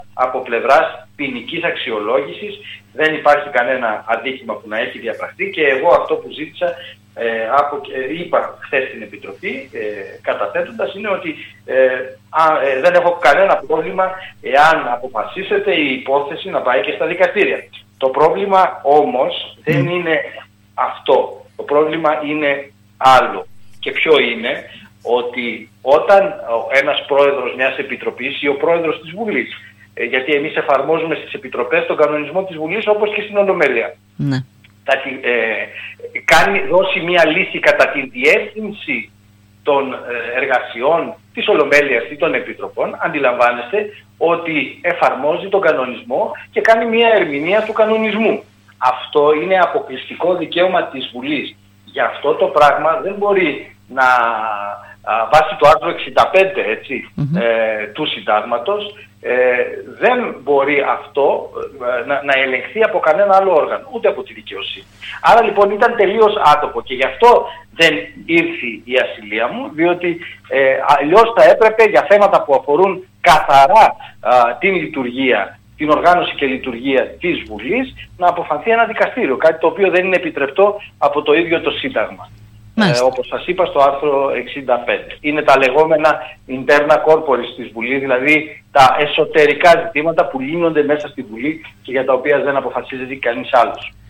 «Η μήνυση ήταν καθαρά για λόγους πολιτικής σκοπιμότητας» δήλωσε ο Βουλευτής Έβρου Σταύρος Κελέτσης μιλώντας σήμερα στην ΕΡΤ Ορεστιάδας σχετικά με την αλλοίωση, όπως του καταλογίσθηκε, των πρακτικών της κατάθεσης του επιχειρηματία Καλογρίτσα στην υπόθεση των τηλεοπτικών αδειών, ως προέδρου τότε,  της προανακριτικής επιτροπής.